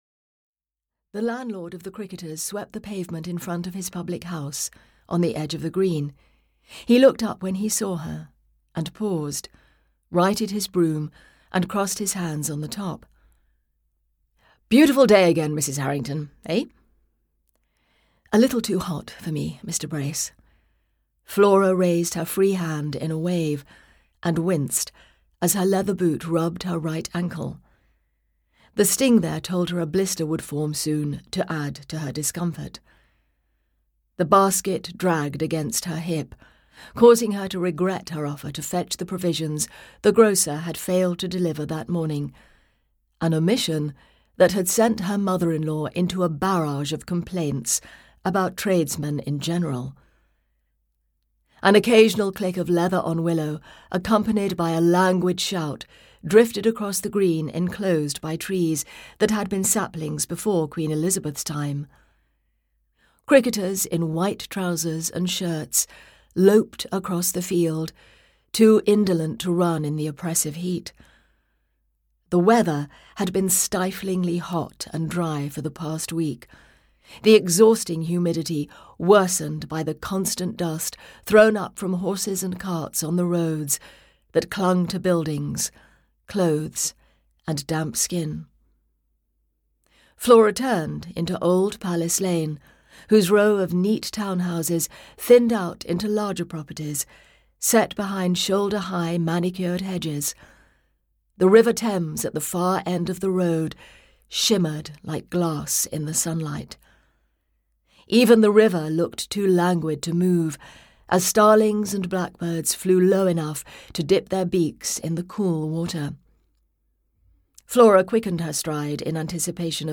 Betrayal at Cleeve Abbey (EN) audiokniha
Ukázka z knihy